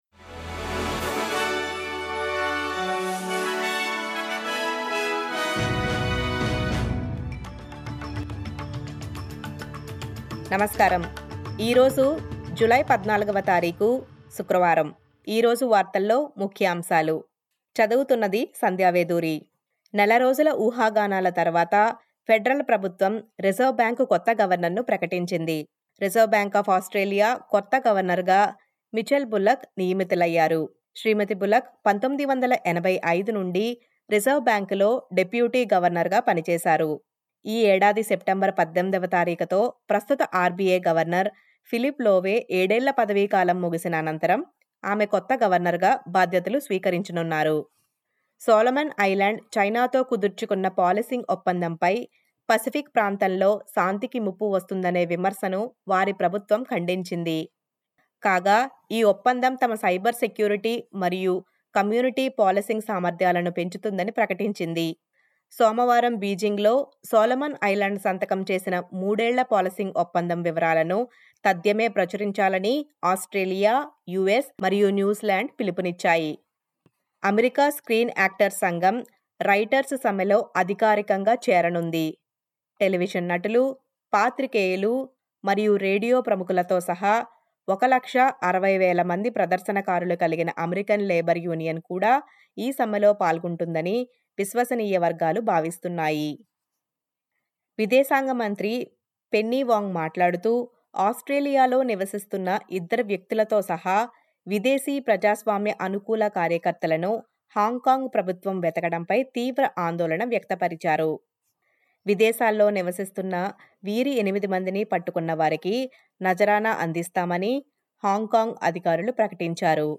SBS తెలుగు 14/07/23 వార్తలు: రిజర్వ్ బ్యాంక్ ఆఫ్ ఆస్ట్రేలియా కొత్త గవర్నర్‌ గా మిచెల్ బుల్లక్ నియమితులయ్యారు